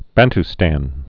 (bănt-stăn)